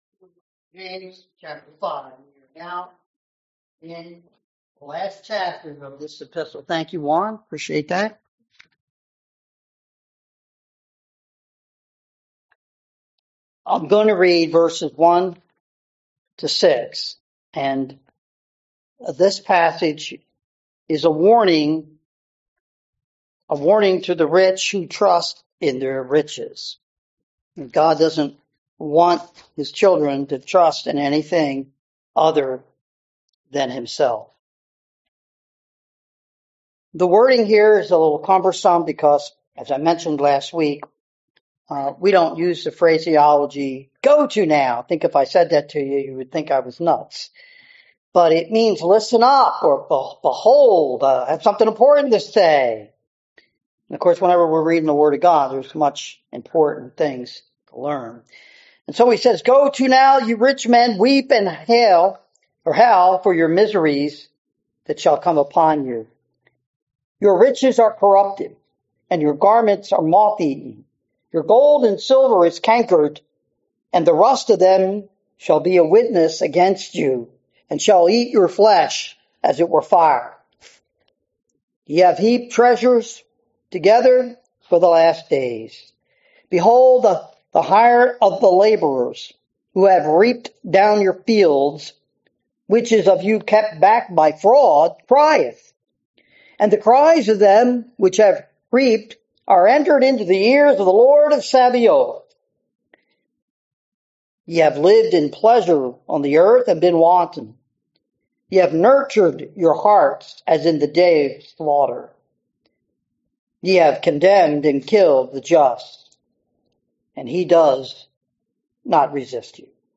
sermon-May-4-2025.mp3